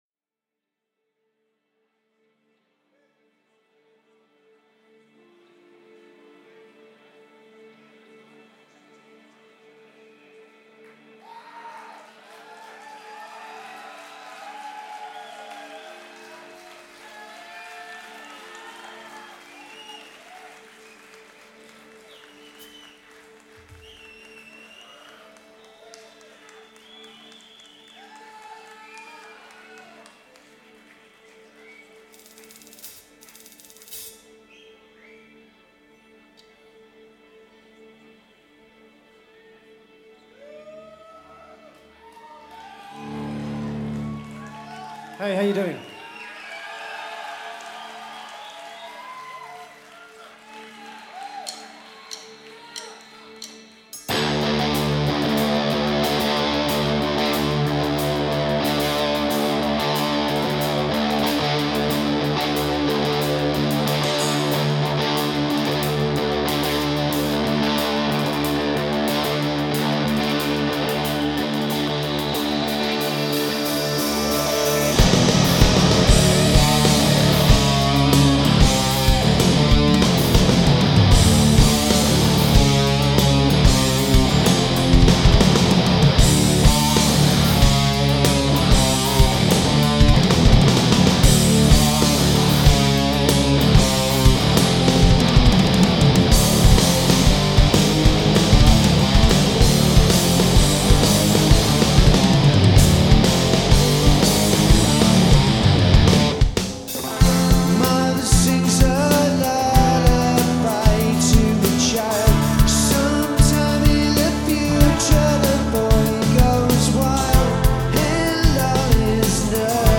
torrid performance
in July 2003 at House of Blues in Los Angeles
neo-prog rockers